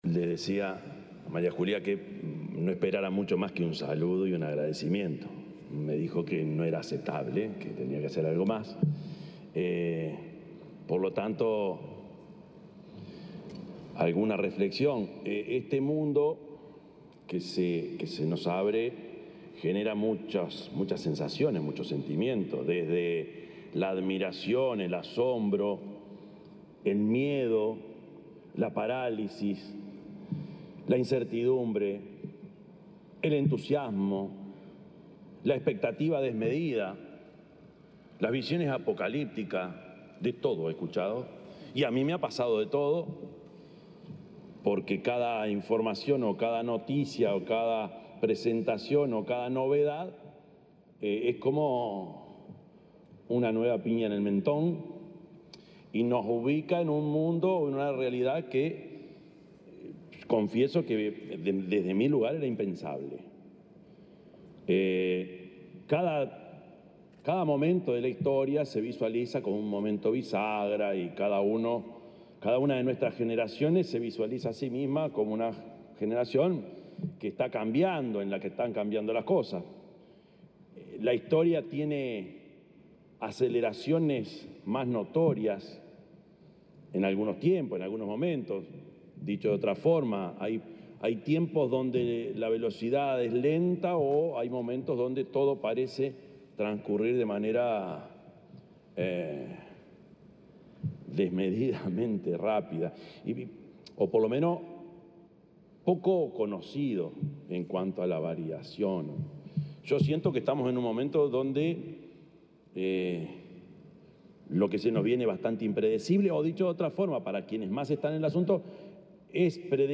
Palabras del presidente, Yamandú Orsi, en Meta Day Uruguay 2025
El presidente de la República, Yamandú Orsi, expuso en Meta Day Uruguay 2025, un evento organizado por Meta, enfocado en marketing digital y